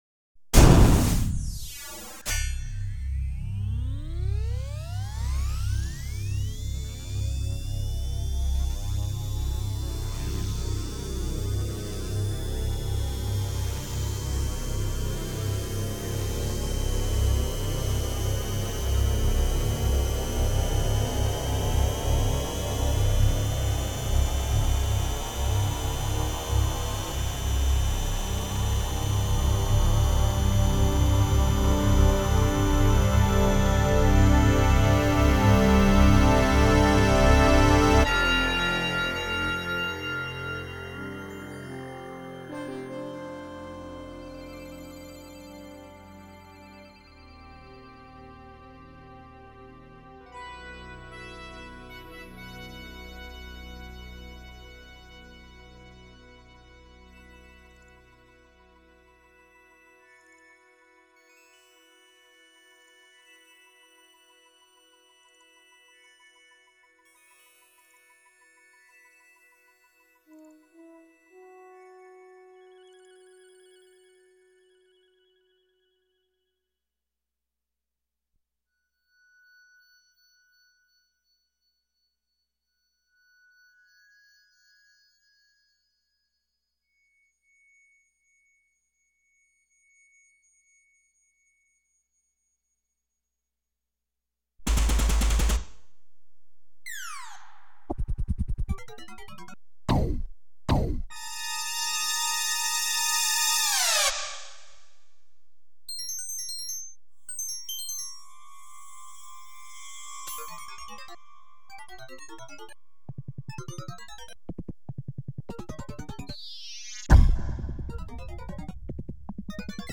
类    别：电影音乐